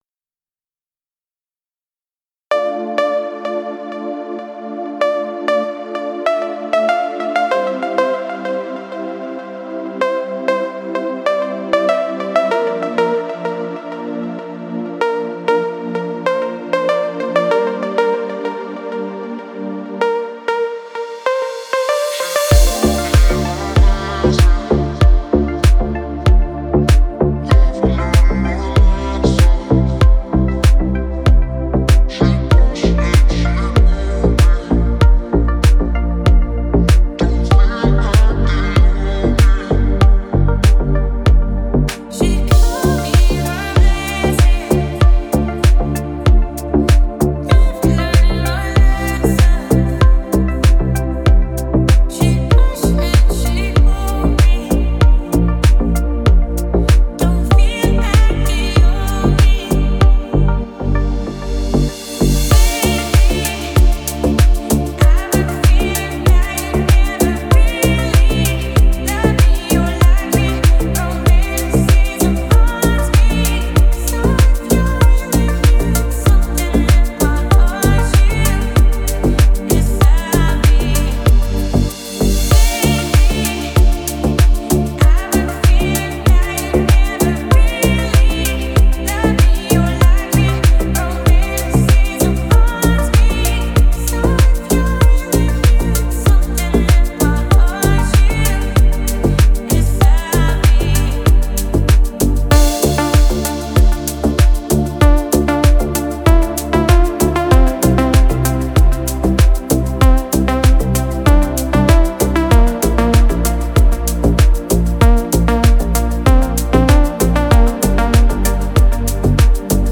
Спокойная музыка
приятные песни